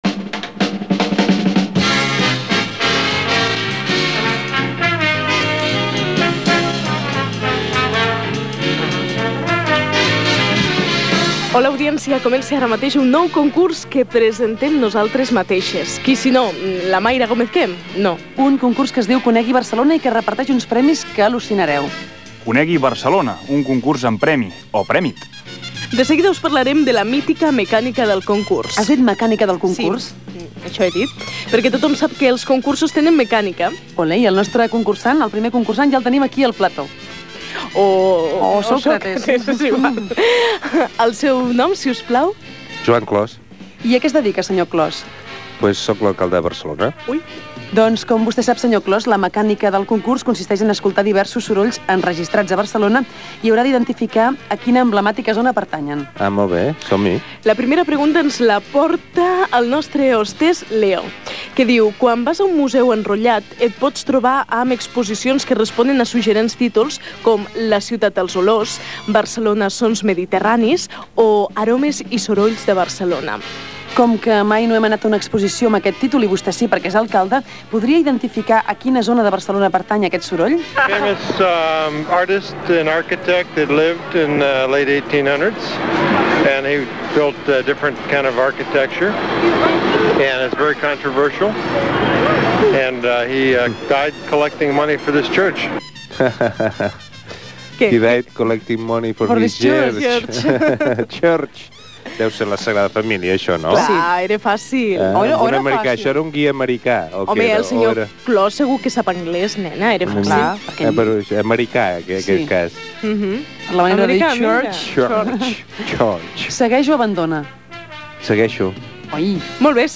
Espai en forma de concurs ("Conegui Barcelona") en el que participa l'alcalde de Barcelona Joan Clos que va responent una sèrie de preguntes, baades en àudios de la ciutat
Entreteniment